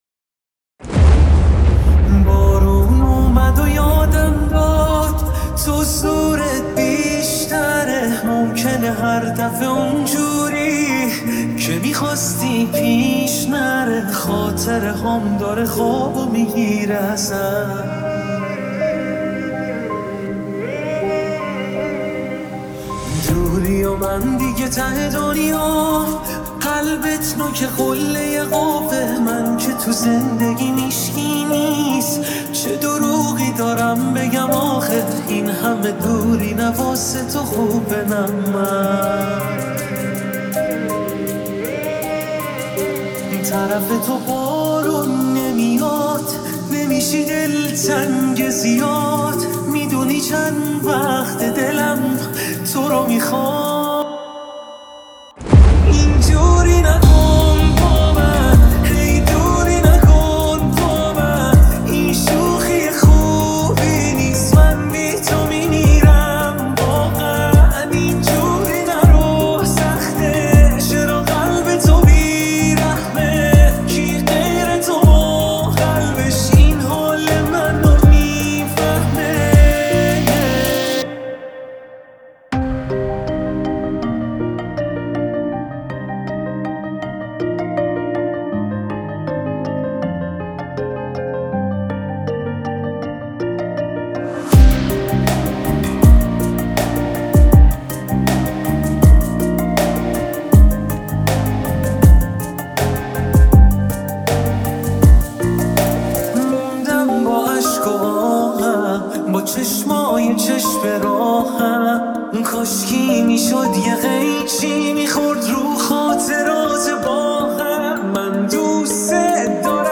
نسخه ریمیکس و آرام تر